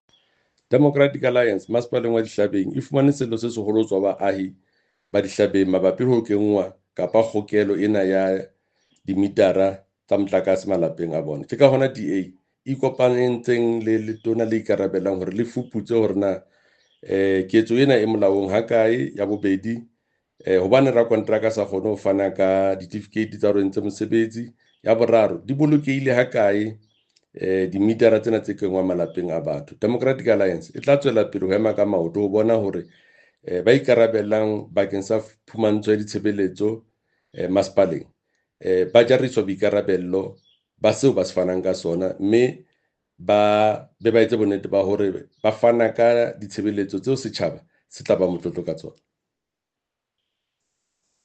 Sesotho soundbite by Jafta Mokoena MPL with images here, here and here